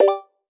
Simple Cute Alert 29.wav